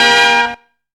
KICKIN HIT.wav